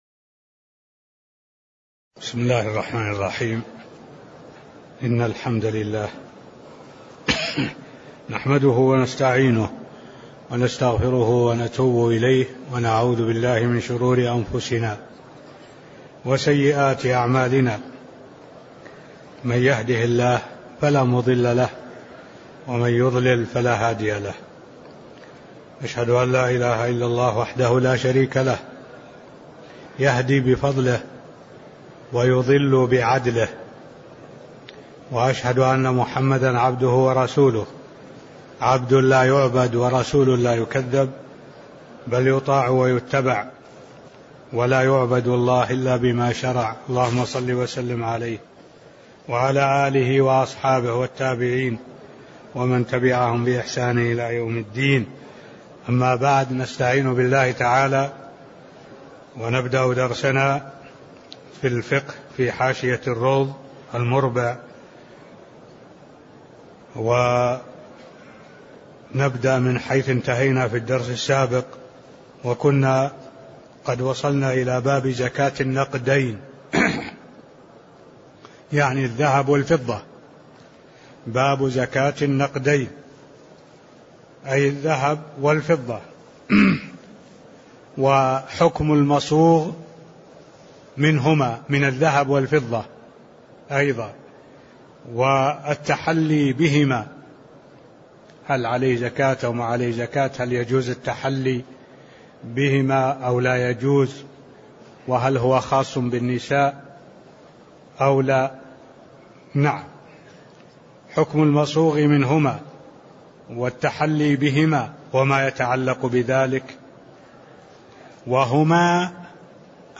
تاريخ النشر ٢٧ ربيع الثاني ١٤٢٩ هـ المكان: المسجد النبوي الشيخ: معالي الشيخ الدكتور صالح بن عبد الله العبود معالي الشيخ الدكتور صالح بن عبد الله العبود باب زكاة النقدين (005)